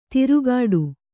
♪ tirugāḍu